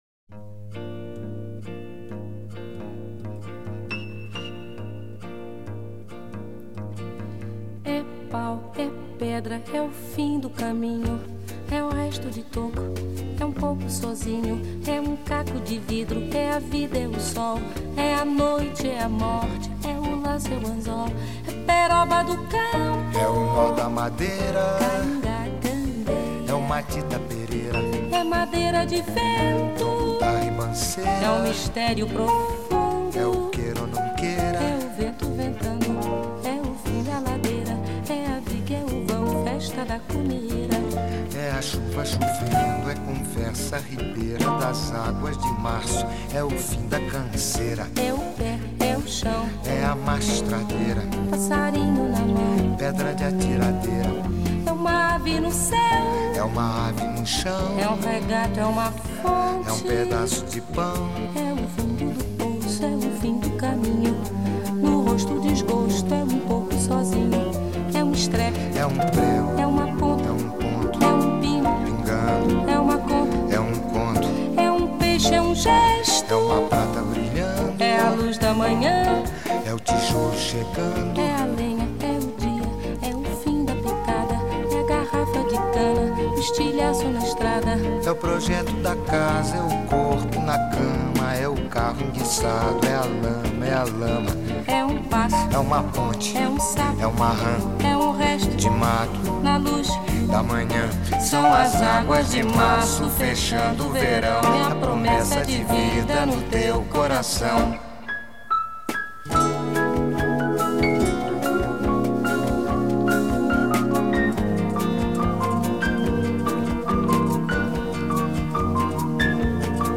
lilting